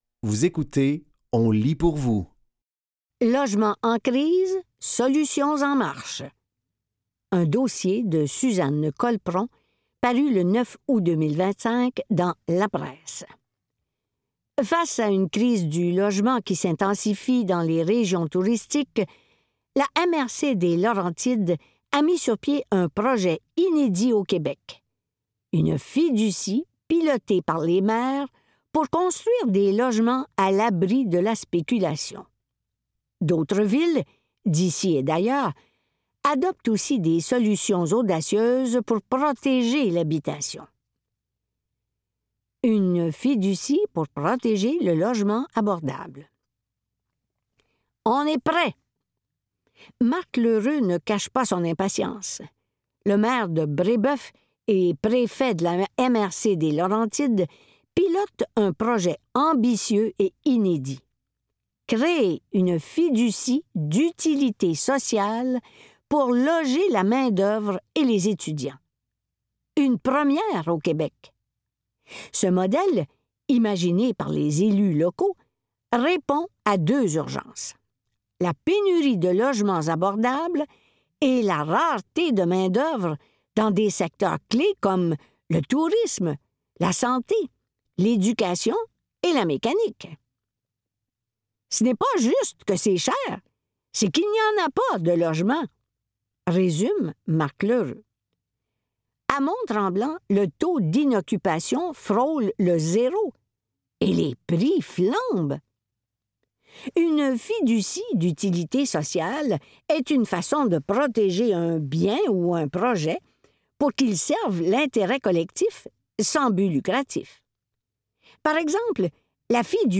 Dans cet épisode de On lit pour vous, nous vous offrons une sélection de textes tirés des médias suivants: La Presse et Le Devoir.